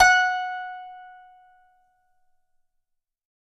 Index of /90_sSampleCDs/Best Service ProSamples vol.52 - World Instruments 2 [AIFF, EXS24, HALion, WAV] 1CD/PS-52 WAV WORLD INSTR 2/STRINGED INSTRUMENTS/PS ZHENG HARP
52-str17-zeng-f#4.wav